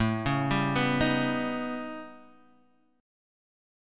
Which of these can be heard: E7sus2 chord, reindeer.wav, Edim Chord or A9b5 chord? A9b5 chord